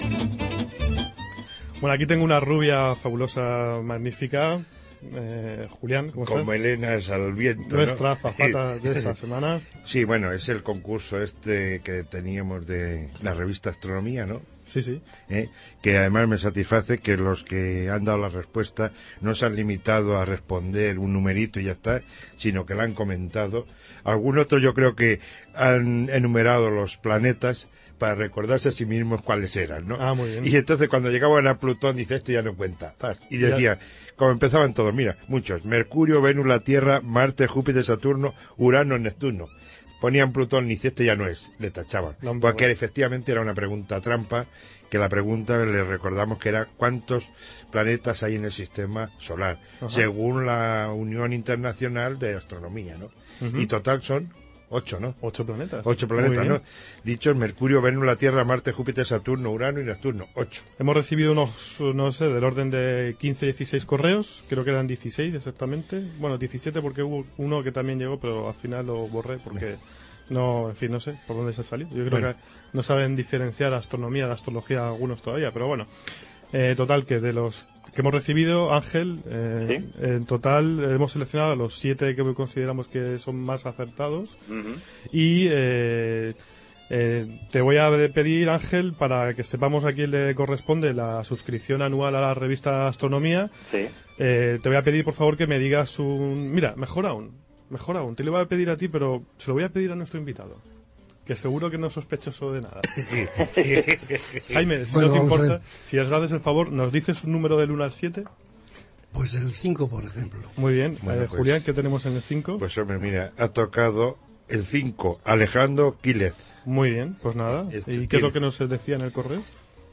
He recortado los fragmentos de la radio, aquí los tenéis.